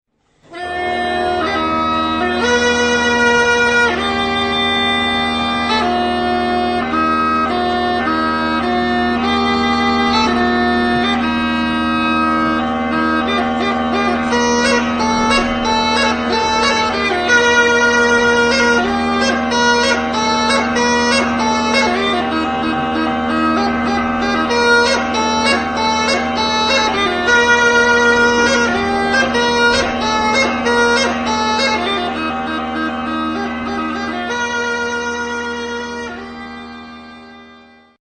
Gayda
Una vez descrito el principio de la gaita, diríamos que el modelo que nos ocupa es una gaita de lengüeta simple.
En las gaydas que nos ocupan hay que señalar la existencia de unas bocinas, que en los extremos de los tubos sonoros, amplifican y dirigen el sonido hacia el intérprete.